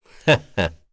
Kain-Vox-Laugh_kr.wav